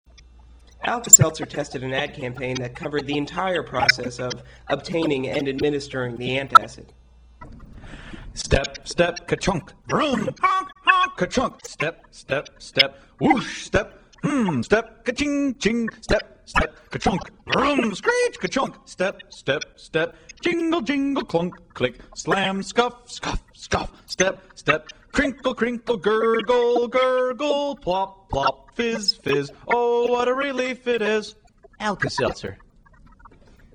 Alka-Seltzer | The onomatopoeic advertisement as first conceived (MP3, 268k)